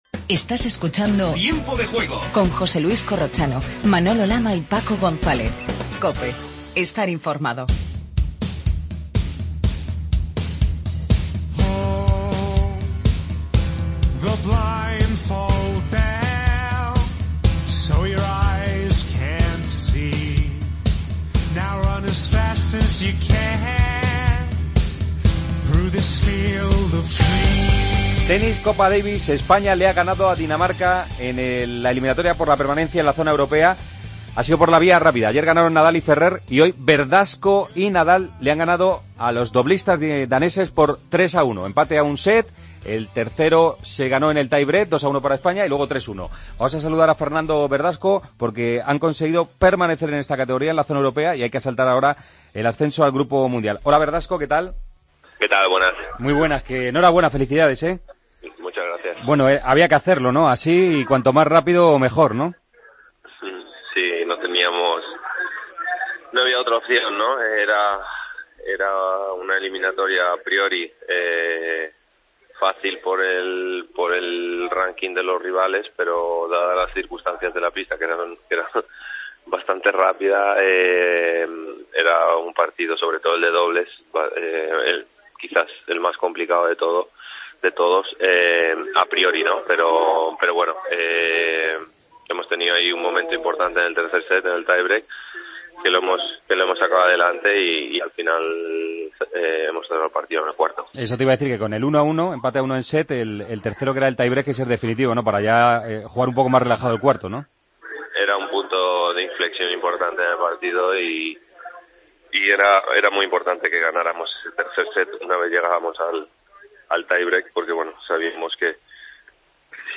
AUDIO: Entrevista a Fernando Verdasco.